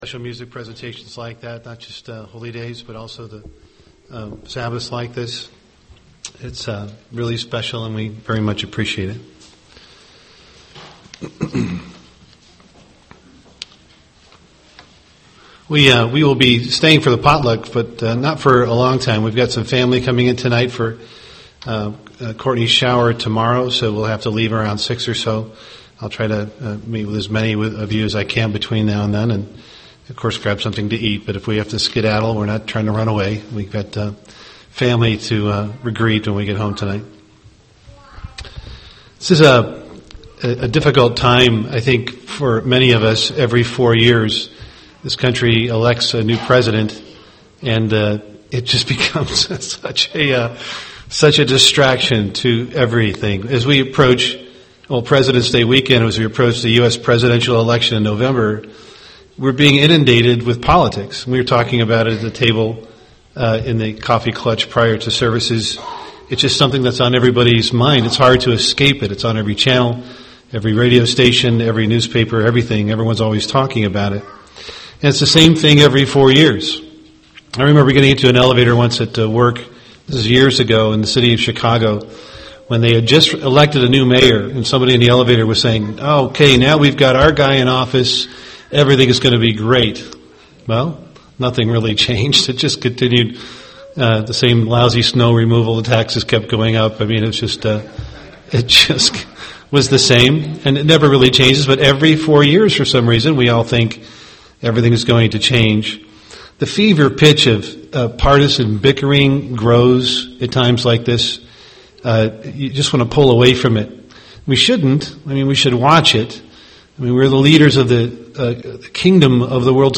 Given in Twin Cities, MN
UCG Sermon Studying the bible?